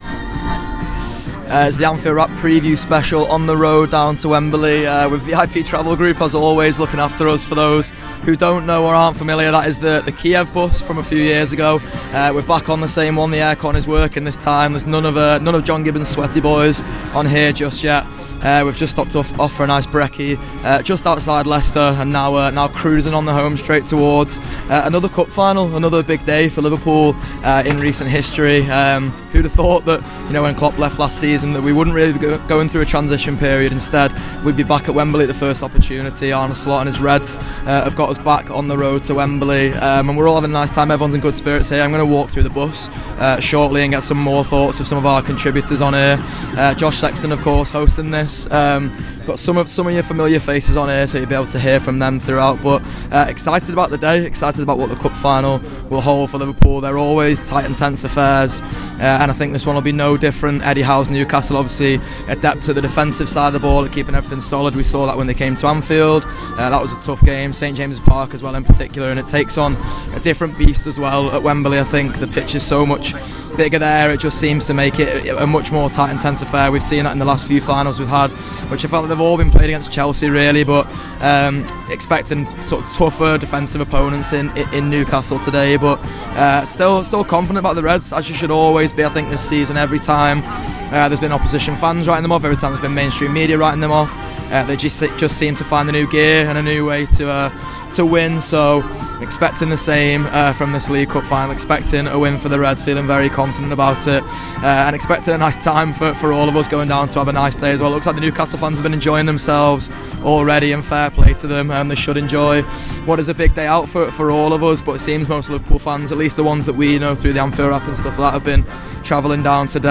A preview special recorded on the TAW bus on the way to Wembley...